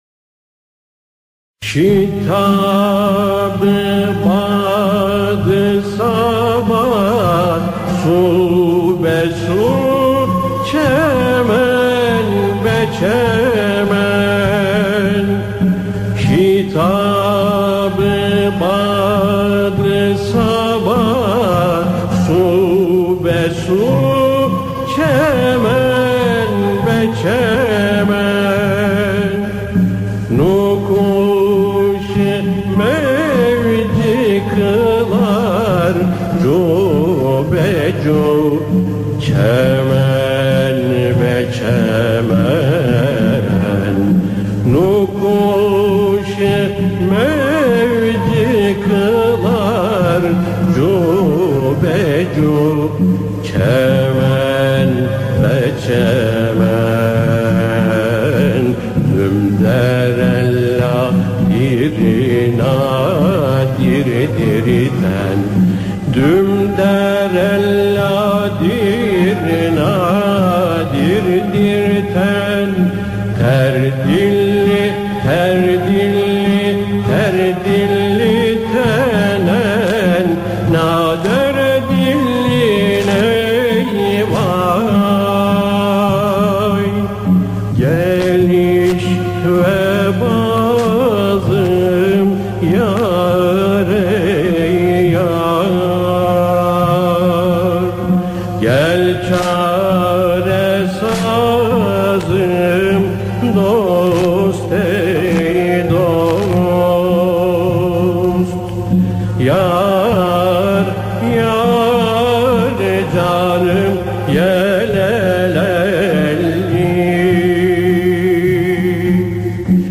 Eser: Şitab-ı bad-ı saba su-be-su çemen-be-çemen Bestekâr: Ebu-Bekir Ağa Güfte Sâhibi: Belirsiz Makam: Nühüft Form: Y.S. Usûl: Yürük Semai Güfte: -...